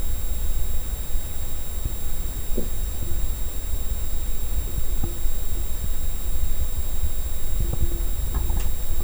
nord-acoustics-vent-whine.wav